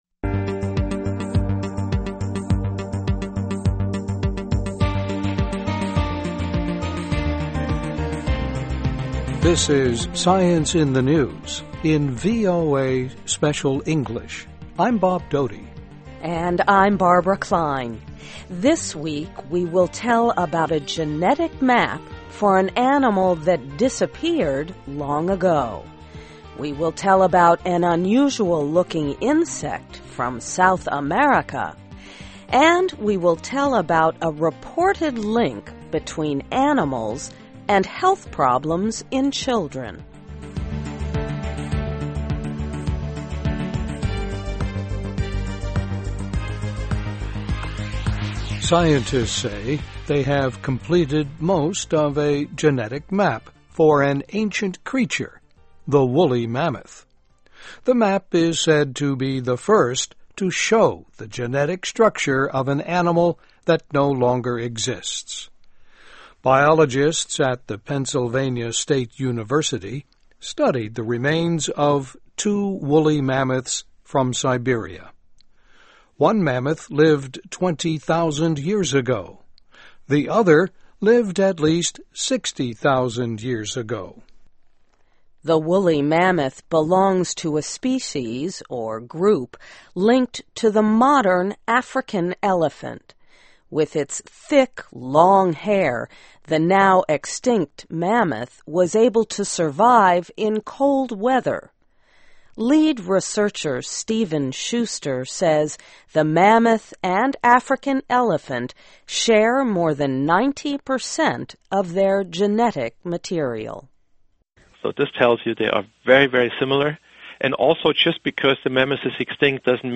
VOA Learning English and VOA Special English helps you learn English with vocabulary, listening, and speaking.